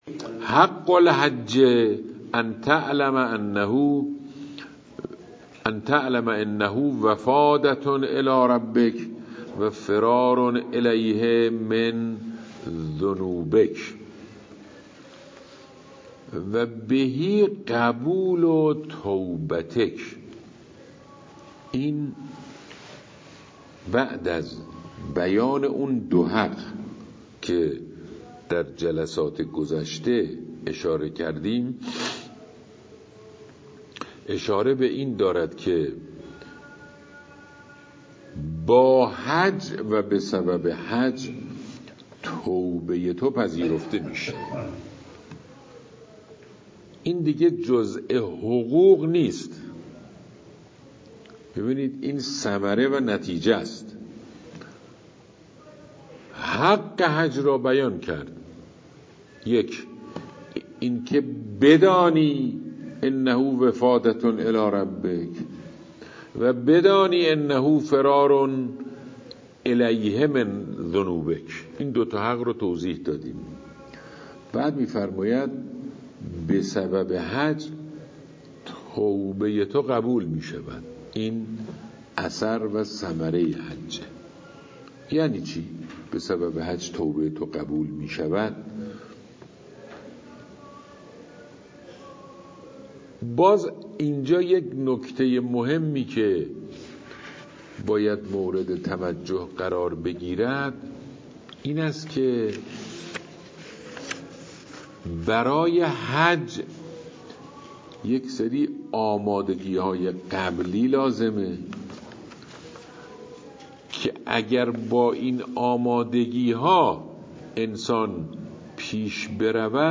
جلسه صد و چهلم